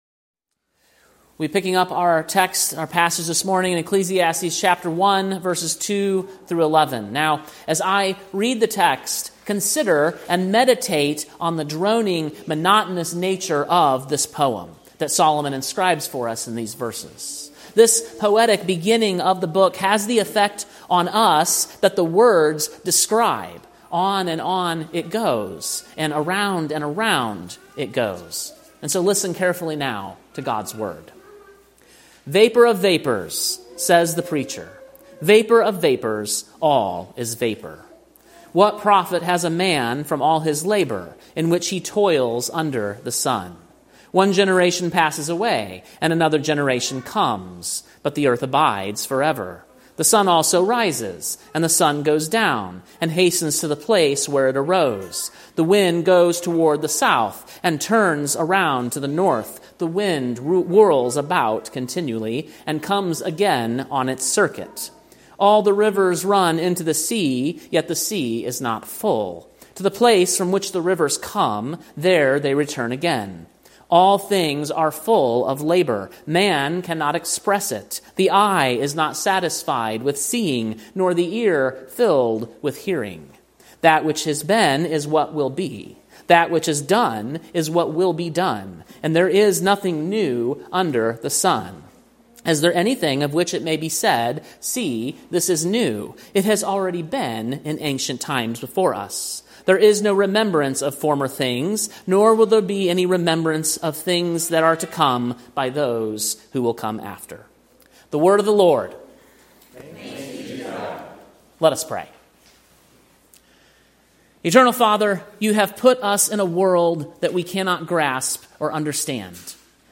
Sermon preached on July 27, 2025, at King’s Cross Reformed, Columbia, TN.